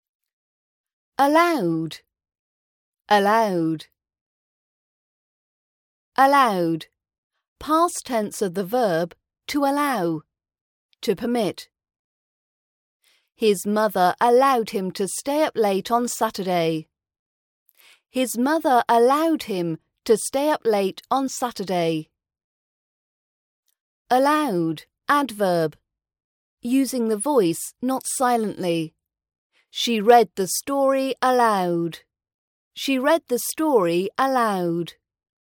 Helping you speak with an RP British accent. Listen to this short british accent training podcast to practice your RP speaking voice.